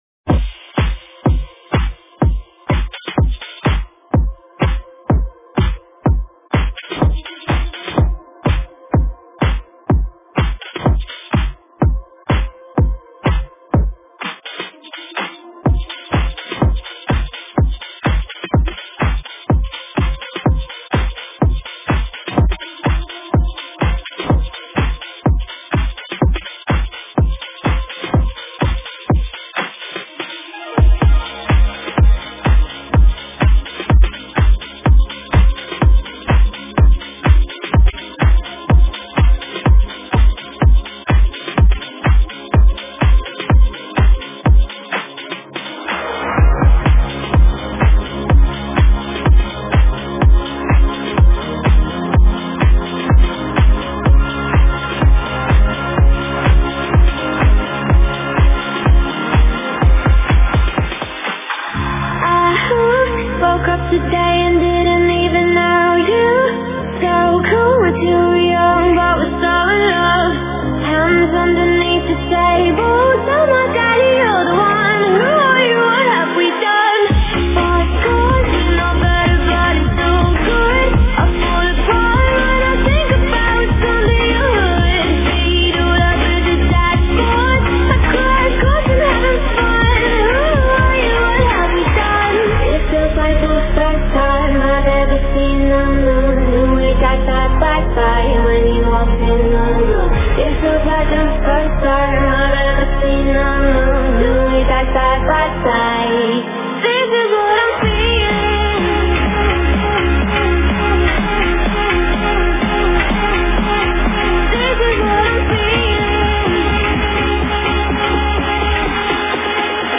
Стиль: Club House / Vocal House / Future House